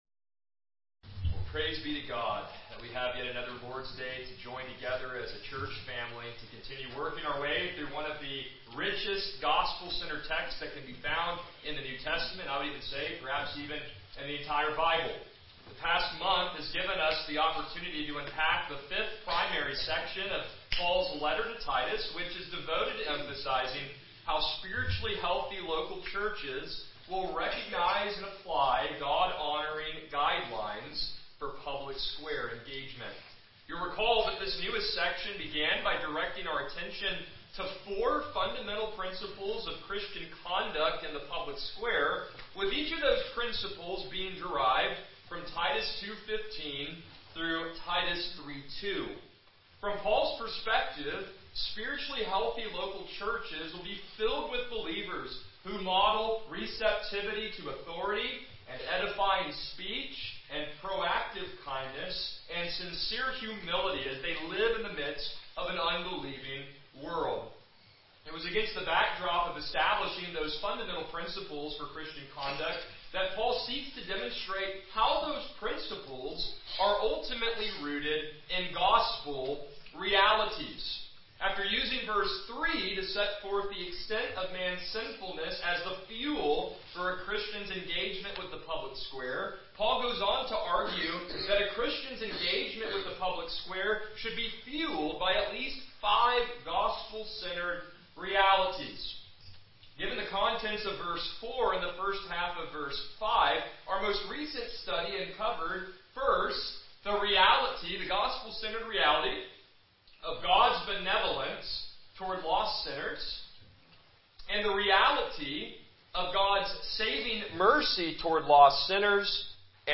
Passage: Titus 3:5b-6 Service Type: Morning Worship